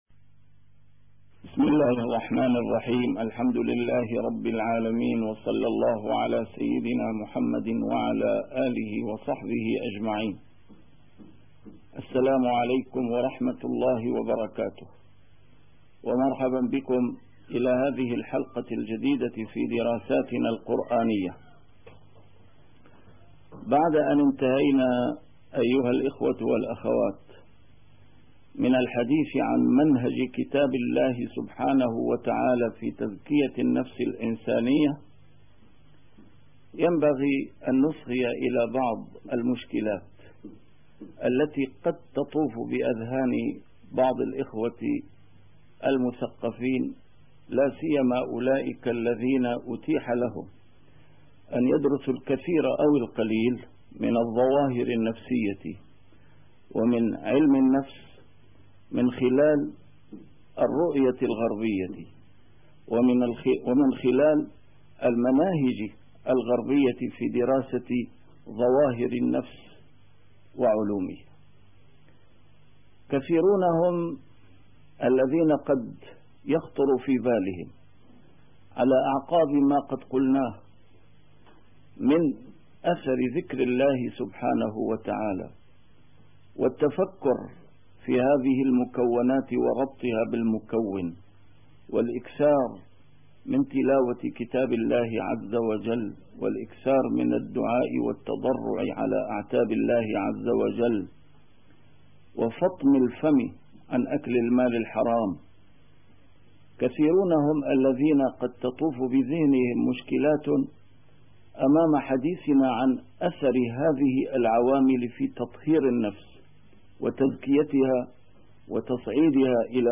A MARTYR SCHOLAR: IMAM MUHAMMAD SAEED RAMADAN AL-BOUTI - الدروس العلمية - دراسات قرآنية - القرآن ومنهج تزكية النفس الإنسانية + مكانة المرآة في كتاب الله عز وجل